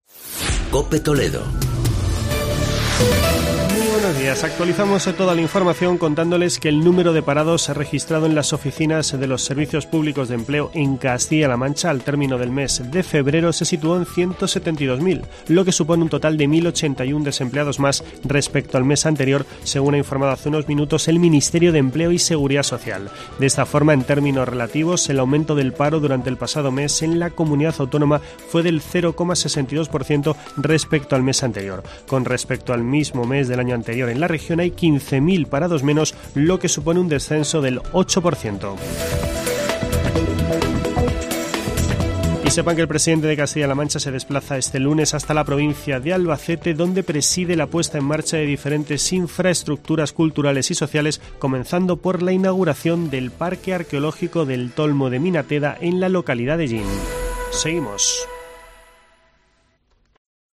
Boletín informativo de la Cadena COPE en la provincia de Toledo.